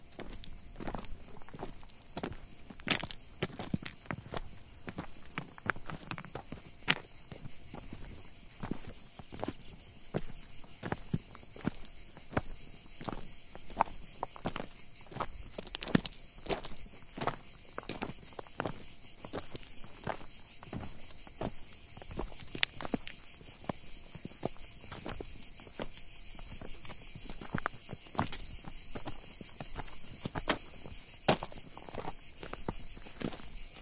Luberon » 3 cicadas cigalles chapelle St Jean
标签： ambience soundscape cicada field recording
声道立体声